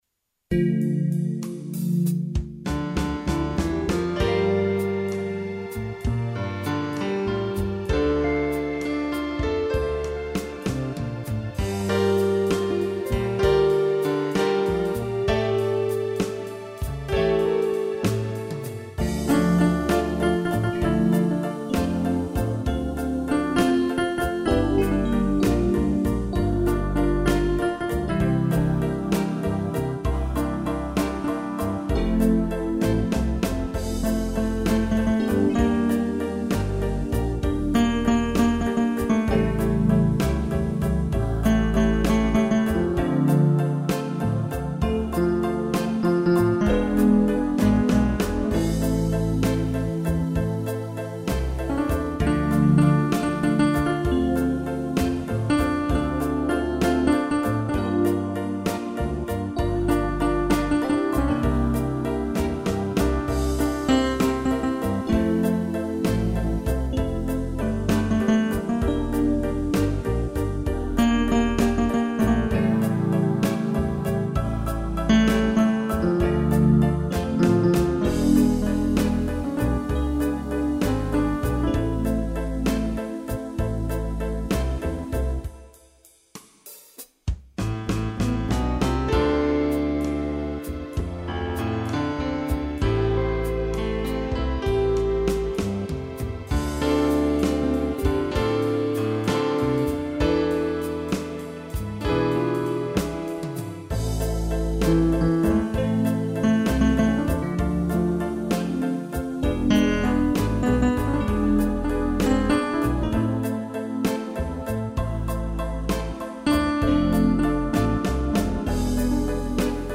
instrumental
piano, sax e string